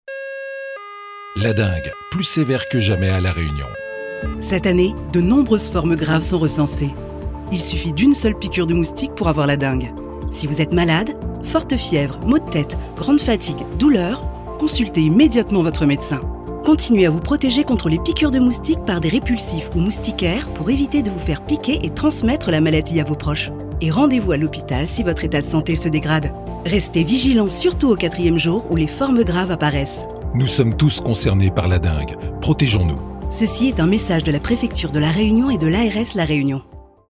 Une campagne radio : téléchargez le spot